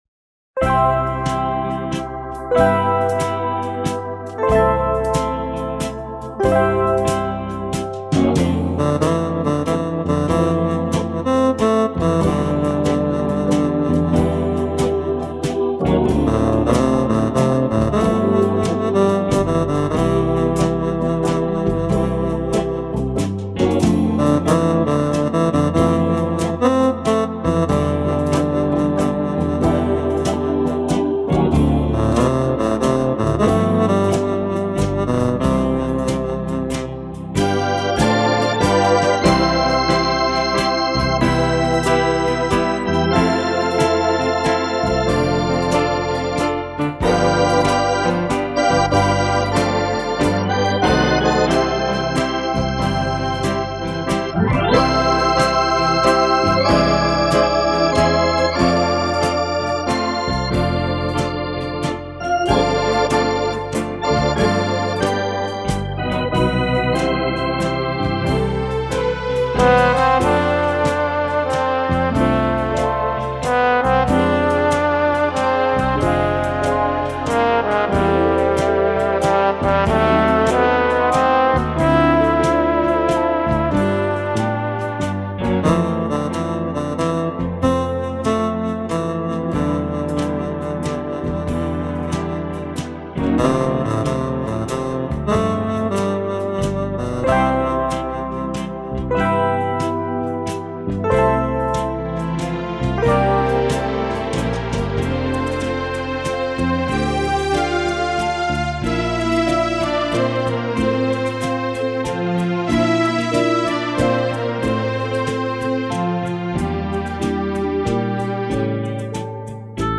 Medley из двух мелодий - "The last waltz" и "Moon river"